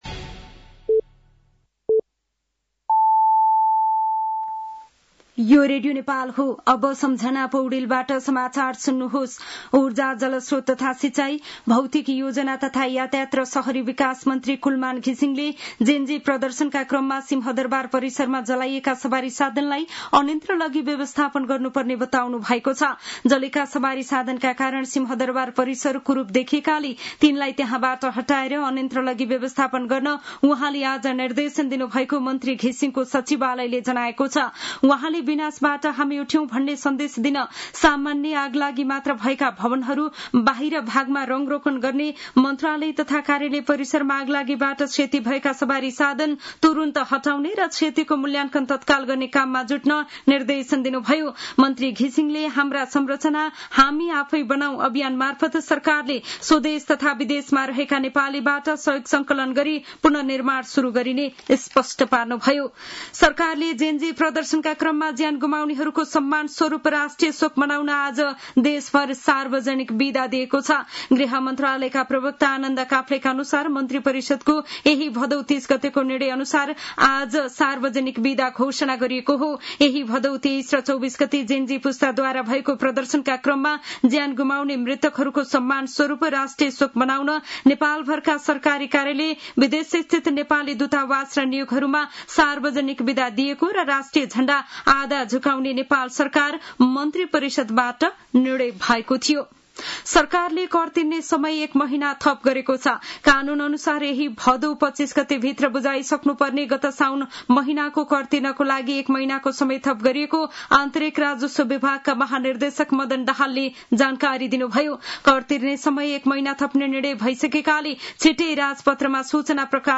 साँझ ५ बजेको नेपाली समाचार : १ असोज , २०८२
5-pm-nepali-news-6-01.mp3